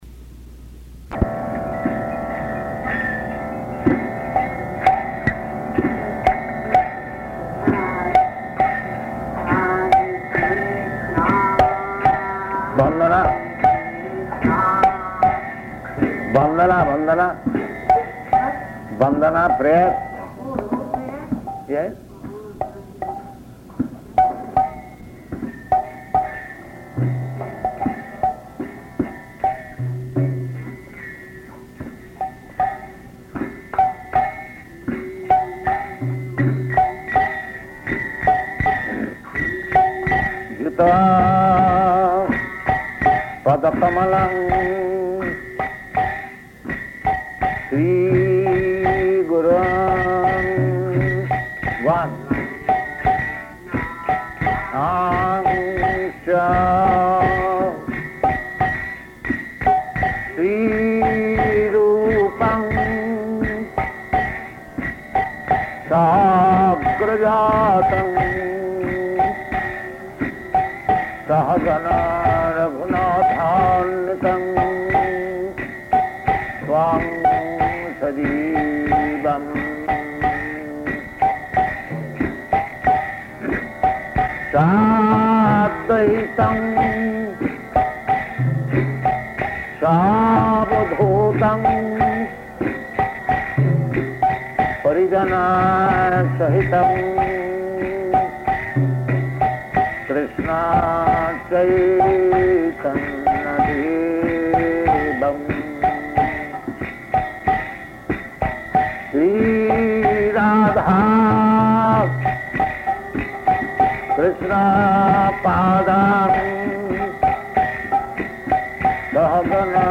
-- Type: Initiation Dated: August 15th 1968 Location: Montreal Audio file